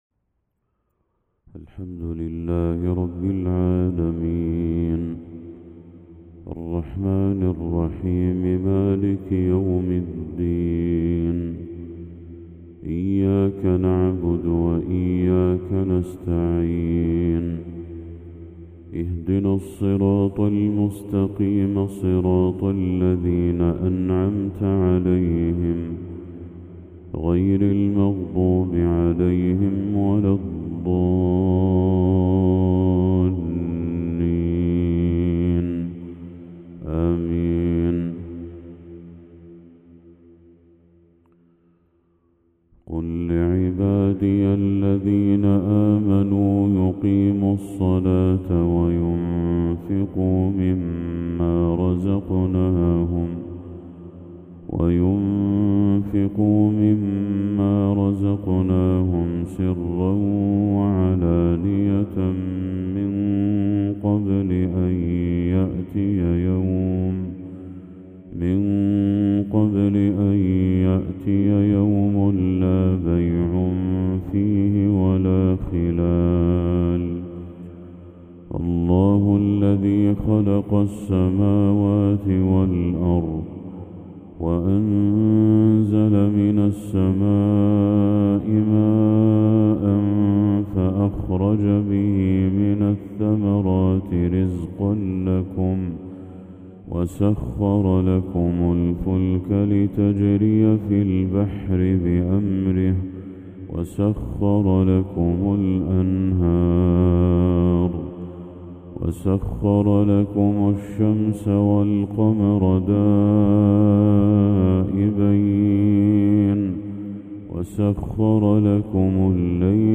تِلاوة خاشعة لخواتيم سورة إبراهيم للشيخ بدر التركي | فجر 23 ذو الحجة 1445هـ > 1445هـ > تلاوات الشيخ بدر التركي > المزيد - تلاوات الحرمين